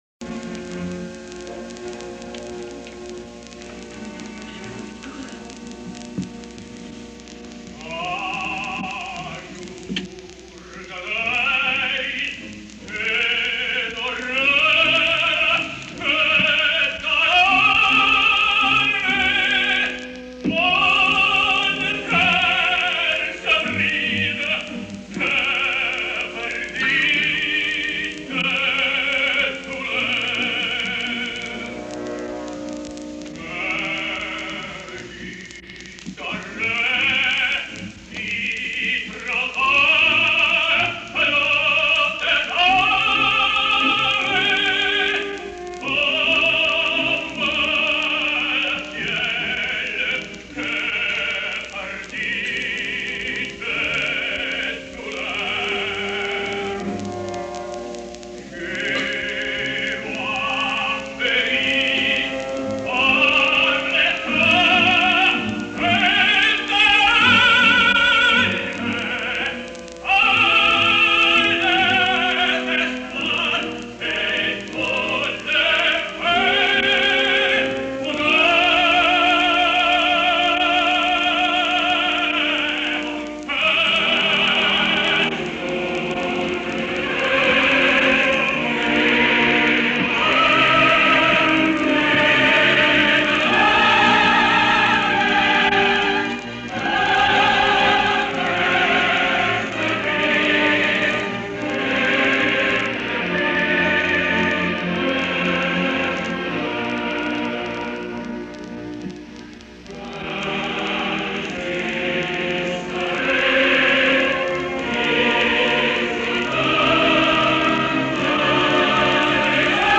Al primer fragment sonor el tenor Franco Corelli canta “Ah, jour de deuil” de Romeo et Juliette de Gounod i està gravat al Met de Nova York el  19 de setembre de 1967.